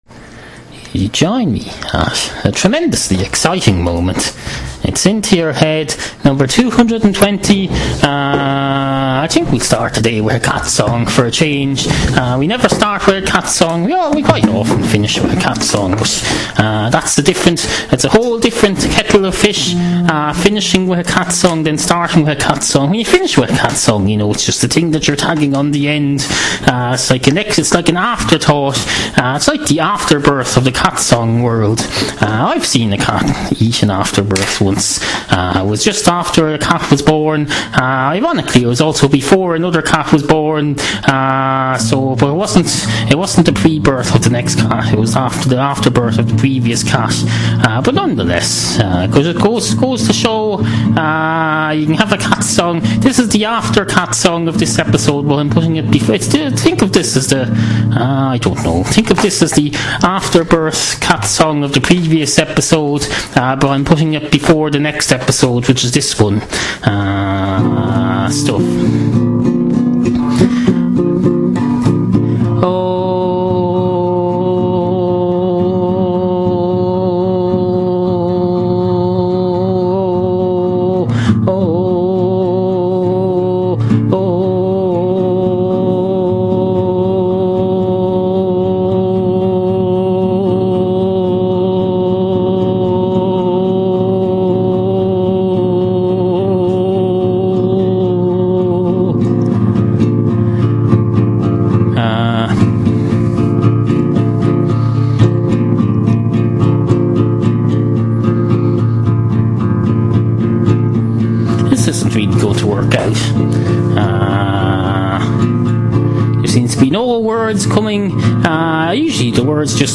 Obscure 21st Century Irish audio comedy series